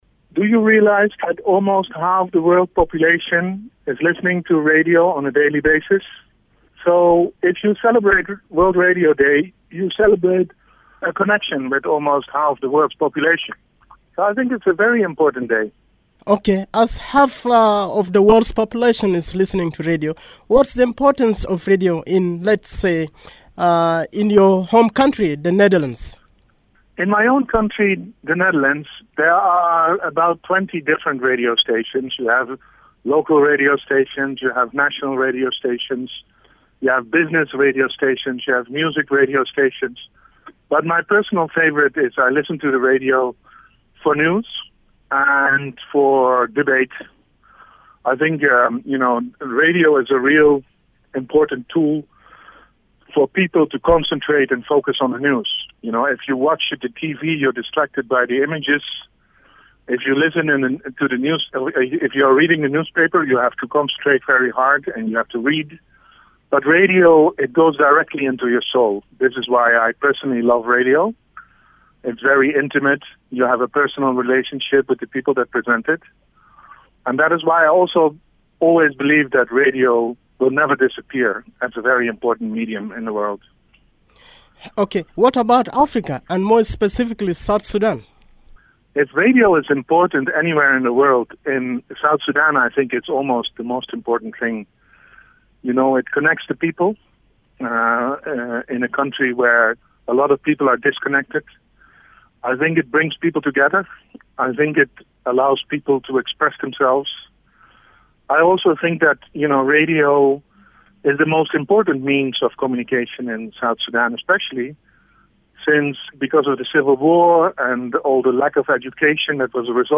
In this powerful interview